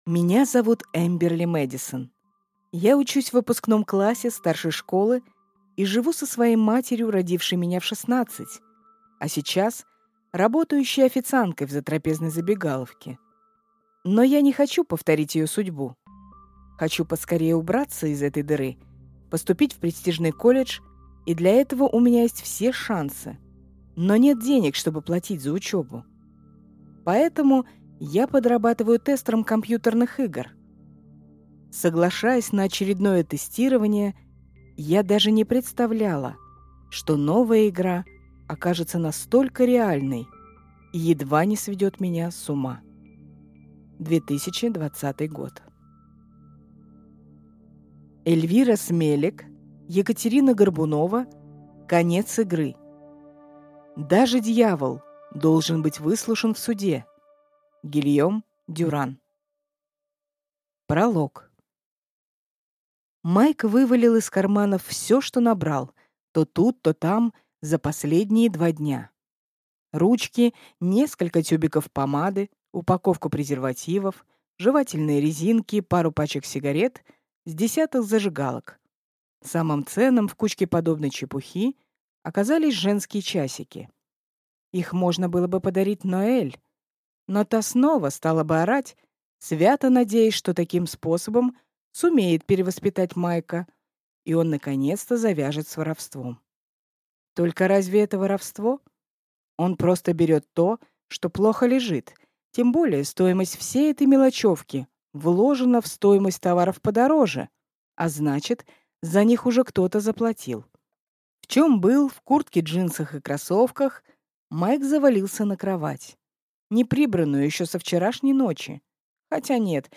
Аудиокнига Конец игры | Библиотека аудиокниг